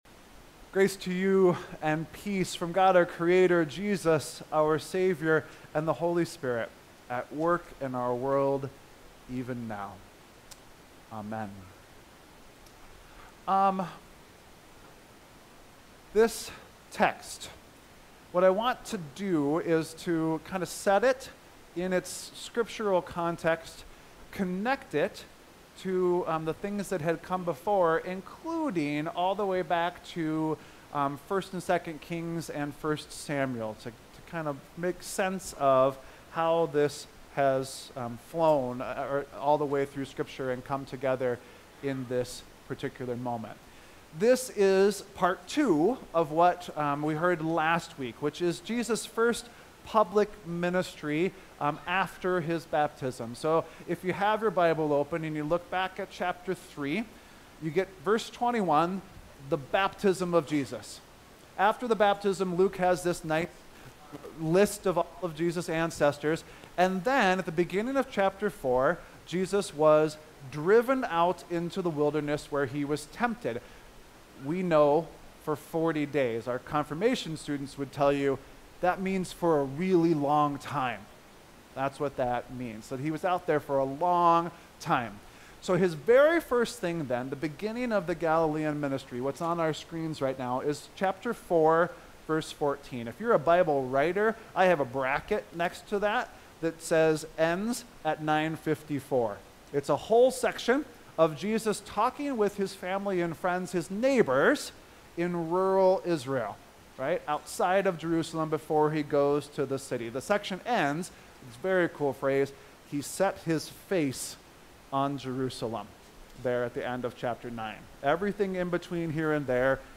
2.2.25-Sermon.mp3